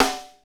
Index of /90_sSampleCDs/Northstar - Drumscapes Roland/DRM_Funk/SNR_Funk Snaresx
SNR FNK S02L.wav